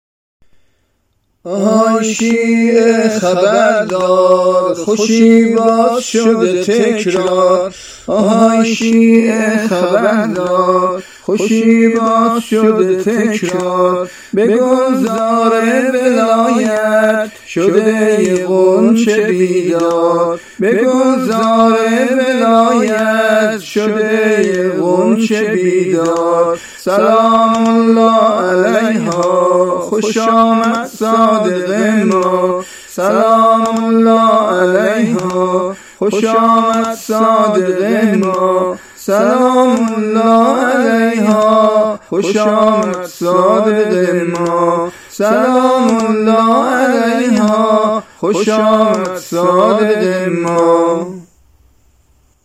سرود میلاد امام صادق(ع)